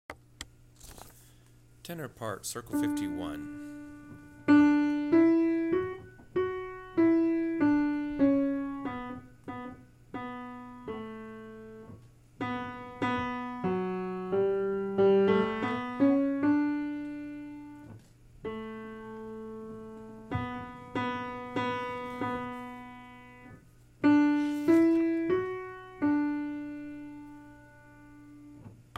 Tenor parts-starting at circle 51 and before circle 80
04 51 tenor part
04-51-tenor-part.wma